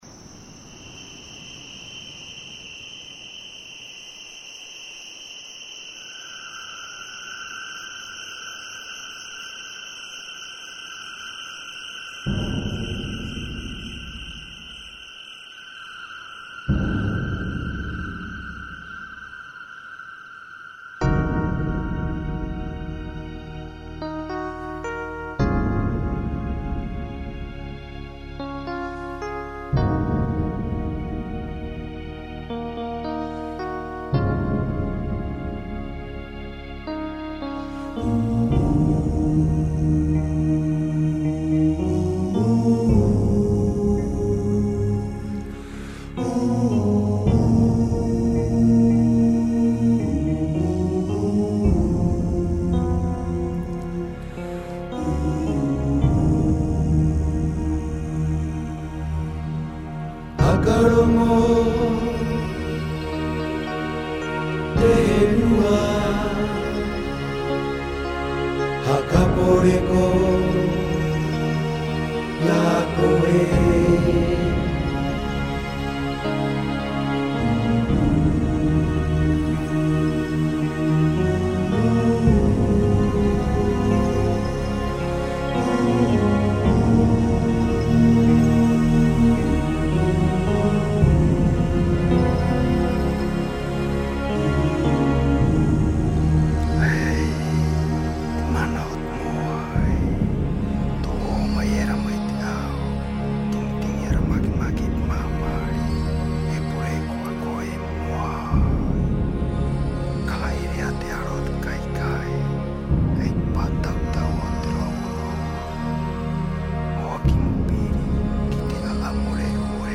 Grabado en la Isla de Rapa Nui, Tahiti y Londres.